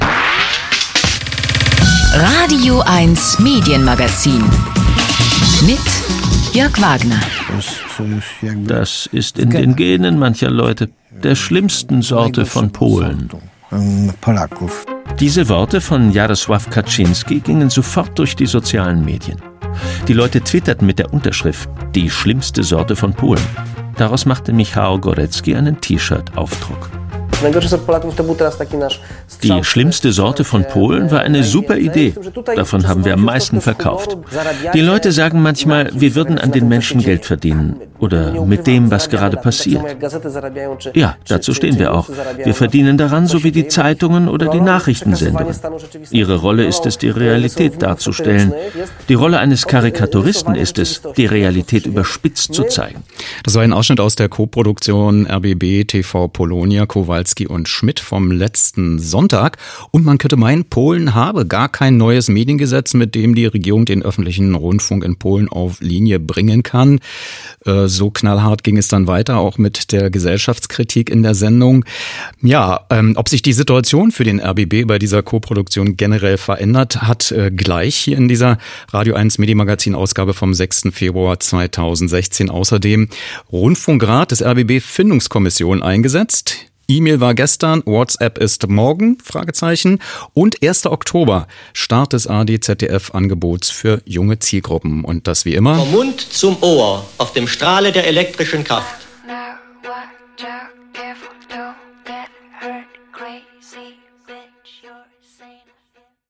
Was: Studiogespräch mit O-Tönen:
Wo: Haus des Rundfunks, Inforadio